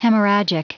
Prononciation du mot hemorrhagic en anglais (fichier audio)
Prononciation du mot : hemorrhagic